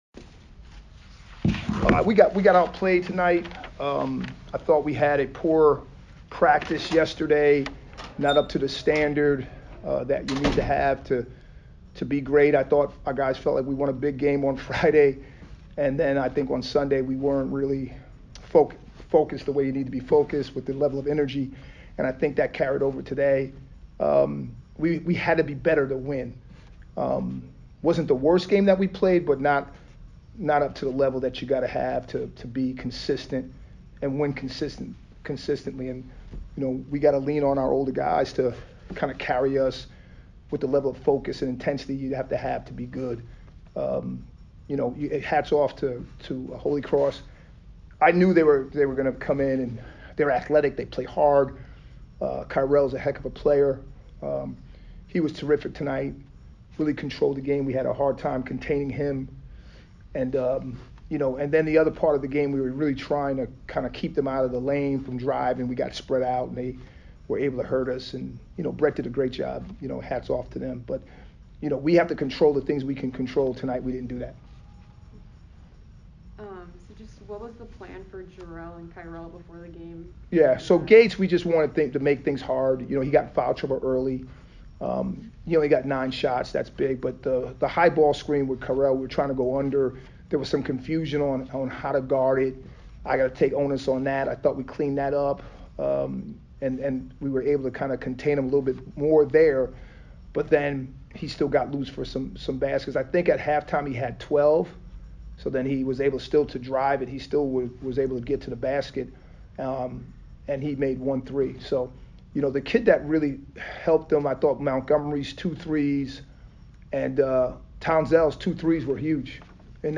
Holy Cross MBB Press Conference